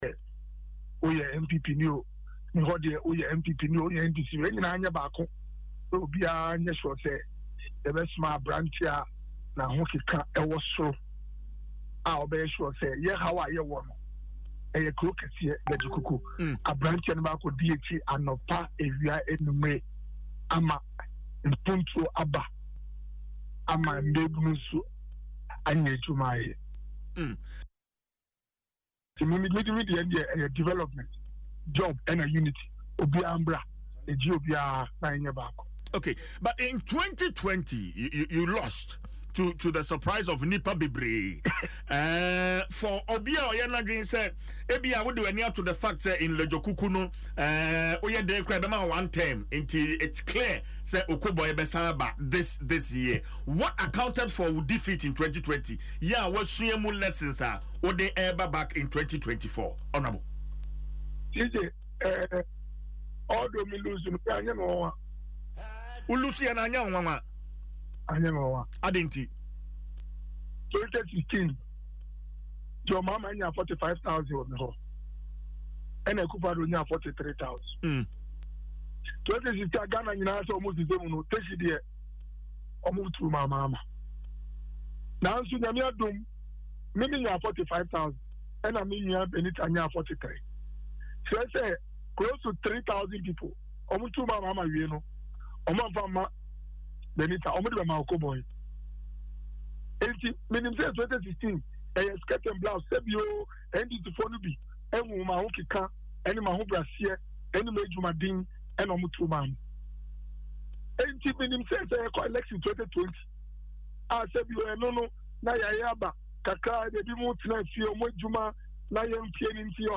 In an interview on Adom FMs morning show Dwaso Nsem Thursday, he said the constituents have tried and tested him and Mr Narteh and are convinced the time has come for change.